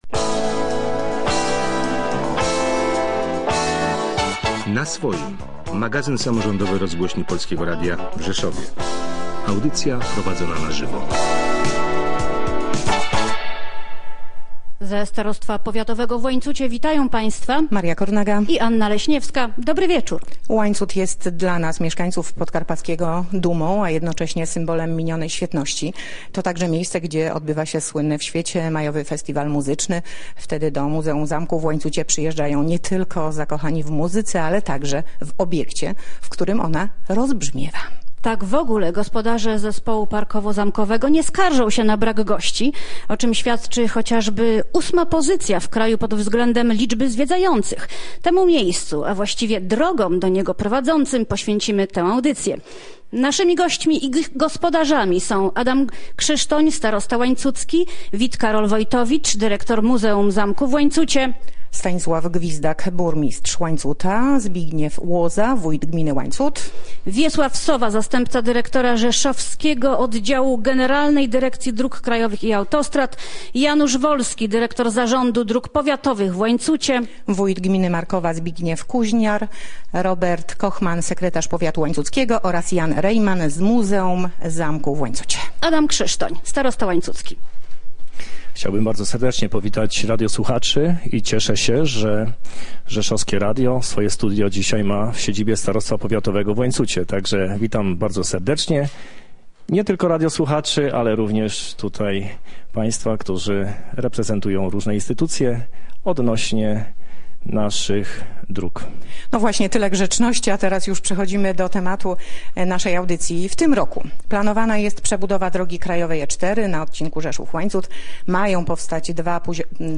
Nagranie - audycja radiowa